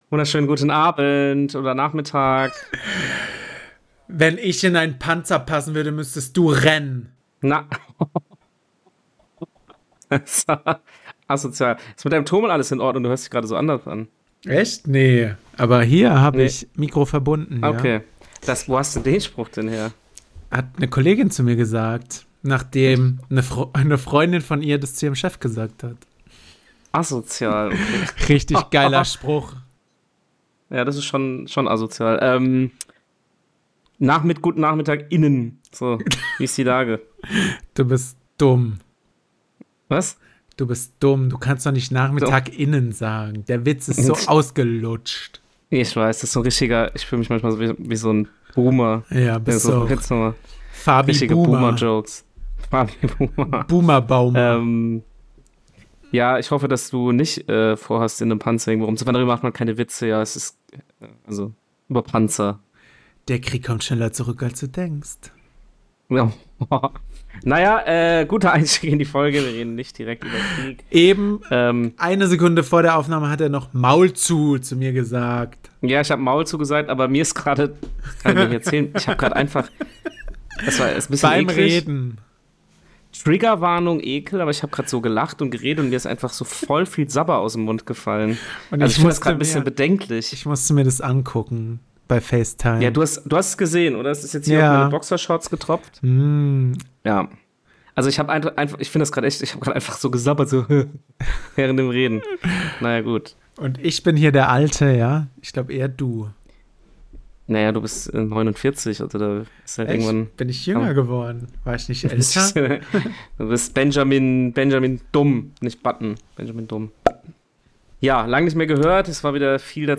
Und liefern Euch ein Pfeifkonzert, das sonst bestimmt 200 EUR Eintritt gekostet hätte for free! Und da man bei uns immer was lernen kann, geht es um Sauerkraut, Oliven und Nudeln.